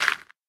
feat(sounds): Added dirt and gravel sound
gravel2.wav